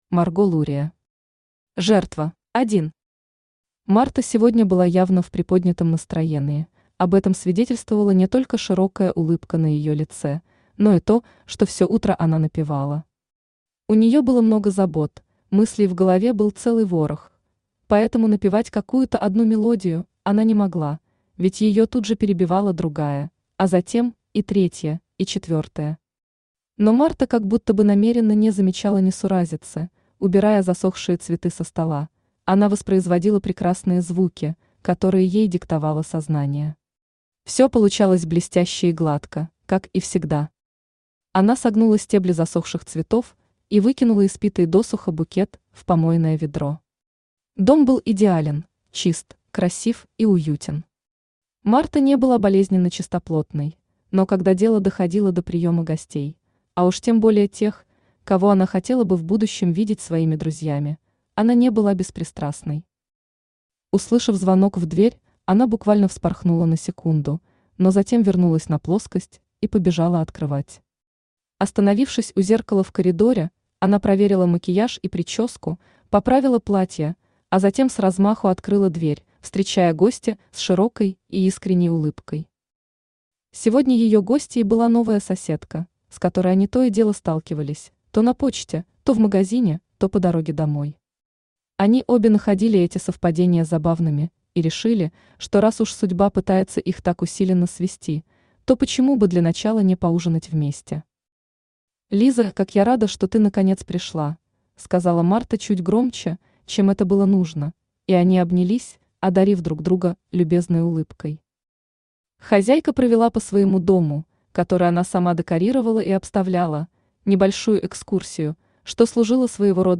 Aудиокнига Жертва Автор Марго Лурия Читает аудиокнигу Авточтец ЛитРес.